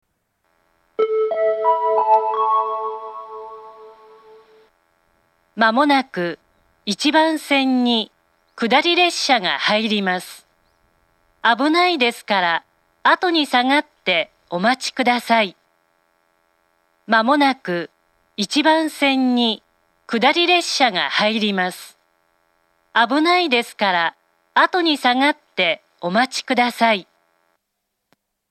１番線接近放送
iwama-1bannsenn-sekkinn3.mp3